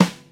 • Warm Sounding Rock Acoustic Snare F Key 398.wav
Royality free steel snare drum tuned to the F note. Loudest frequency: 979Hz
warm-sounding-rock-acoustic-snare-f-key-398-UeR.wav